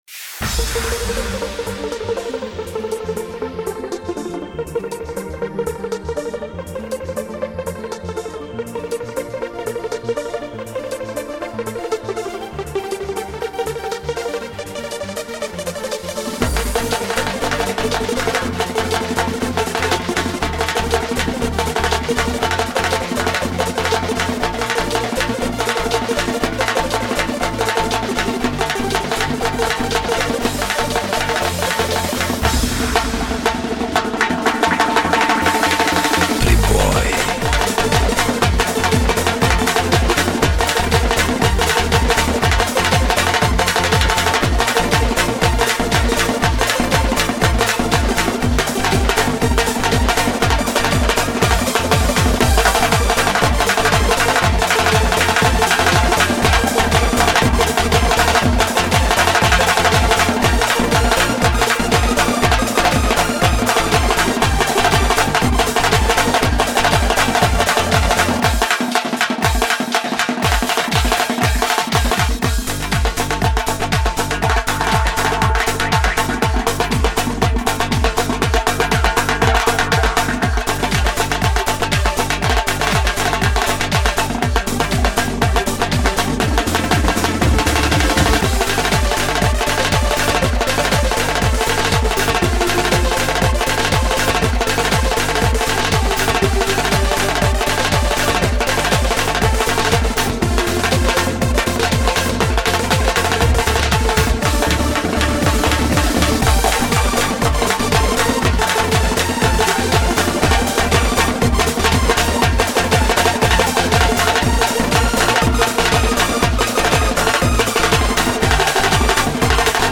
Лезгинка зажигательная на выбор:
Barabany_2.mp3